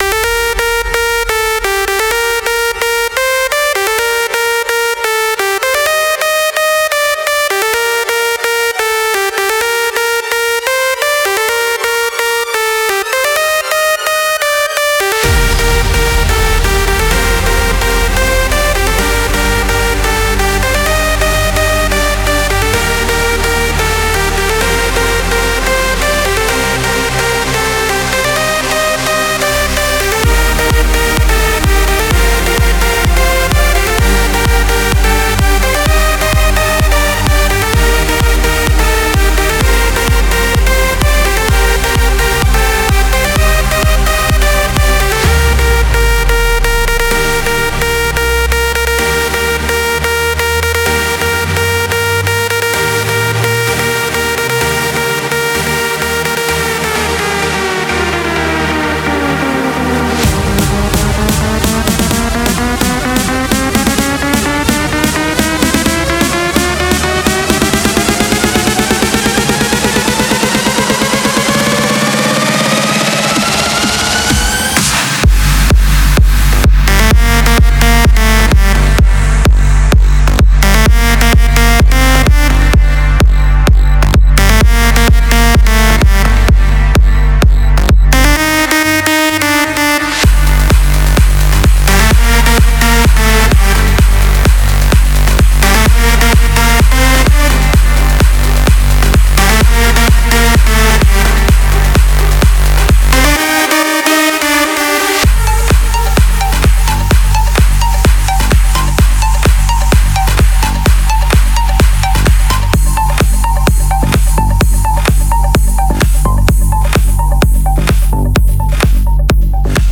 BPM64-128
Audio QualityMusic Cut
it's a big room banger.